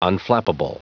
Prononciation du mot unflappable en anglais (fichier audio)
unflappable.wav